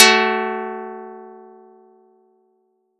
Audacity_pluck_10_13.wav